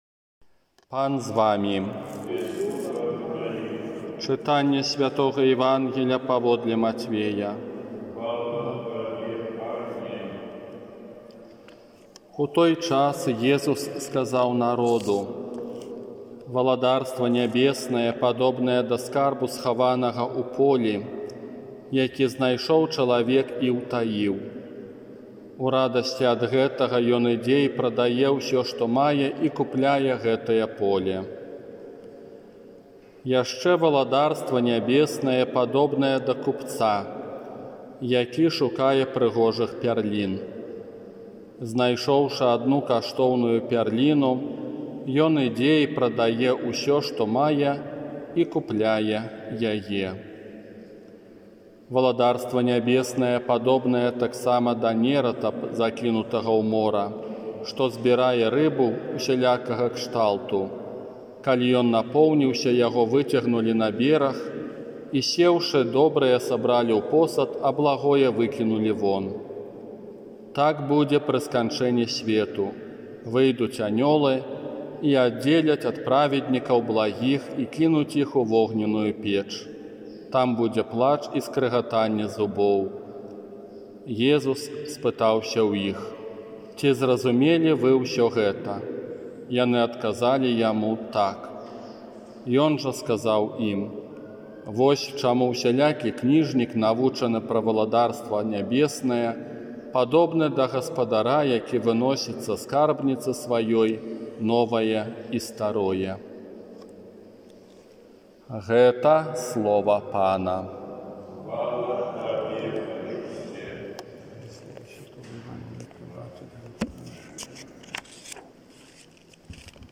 Казанне на сямнаццатую звычайную нядзелю